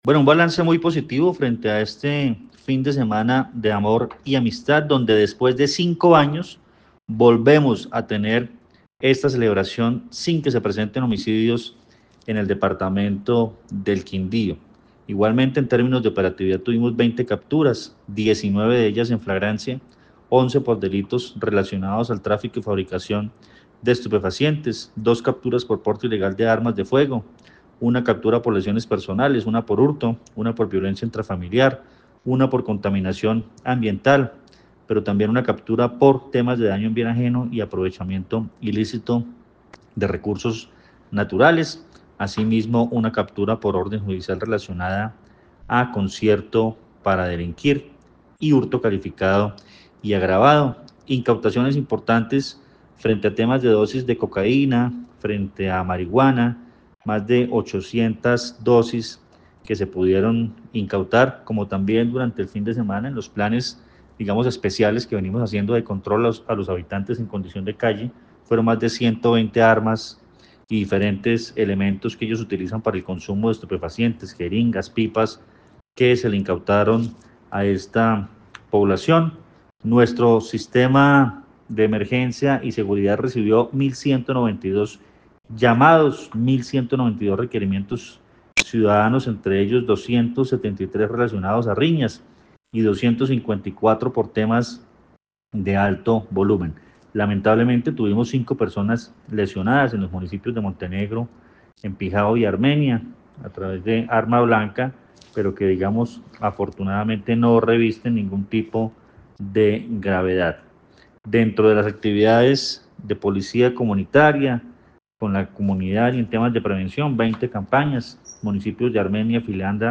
Coronel Luis Fernando Atuesta
El coronel Luis Fernando Atuesta, comandante de la Policía en el Quindío enfatizó en que después de cinco años es relevante destacar que no se presentaron hechos de afectación a la vida.